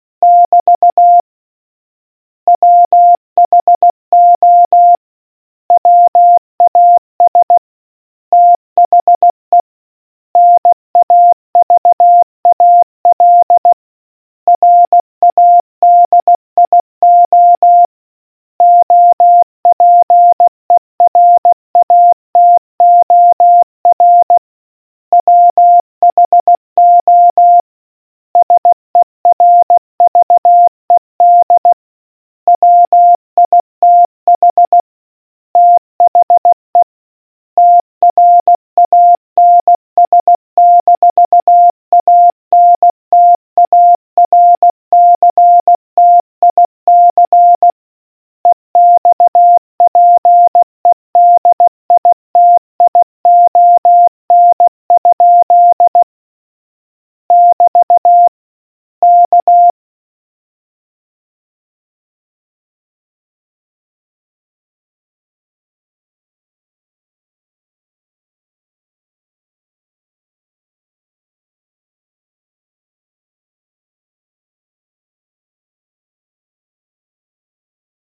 Morse Question 007